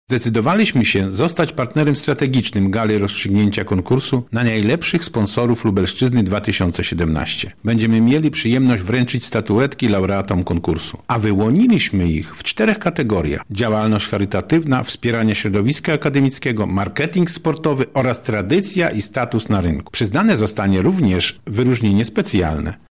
O szczegółach mówi Sławomir Sosnowski, Marszałek Województwa: